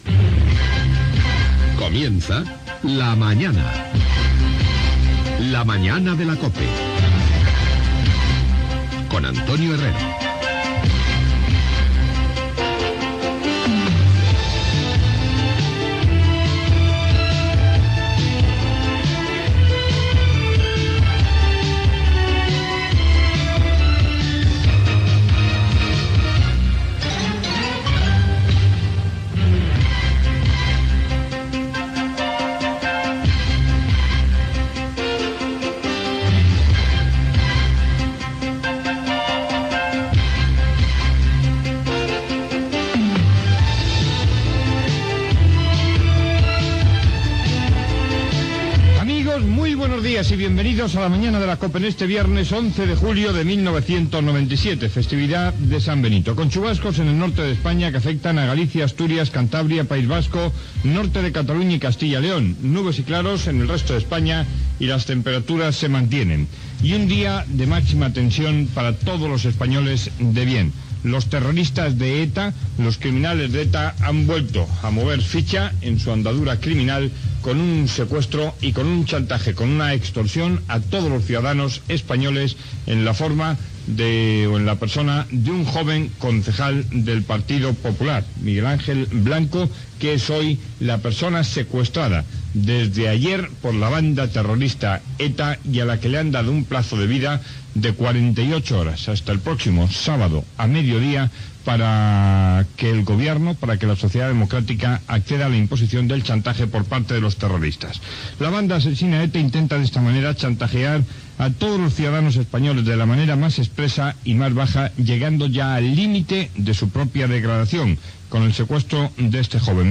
Careta del programa, data, el temps, segrest del regidor Miguel Ángel Blanco, del Partido Popular, per la banda terrorista ETA, declaracions del ministre d'Interior espanyol Jaime Mayor Oreja
Info-entreteniment